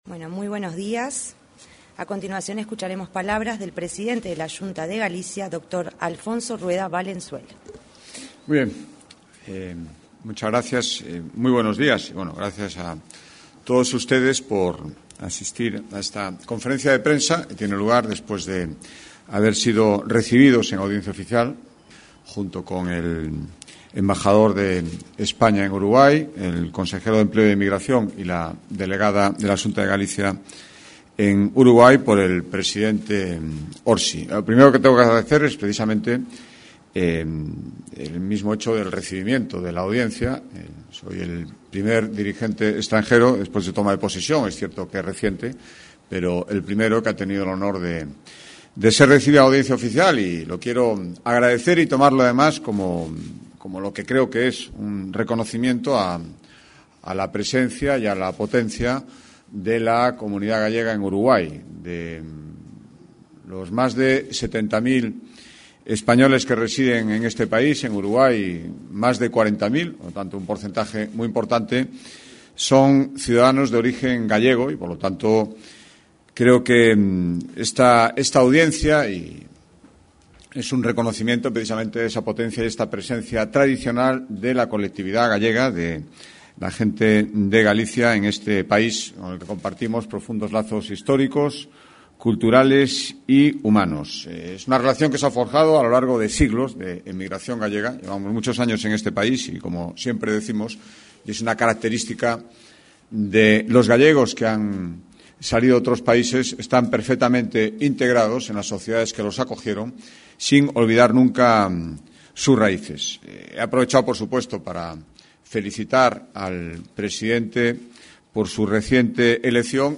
Conferencia de prensa del presidente de la Xunta de Galicia
Este lunes 10, el presidente de la Xunta de Galicia realizó una conferencia en la sala de prensa de la Torre Ejecutiva.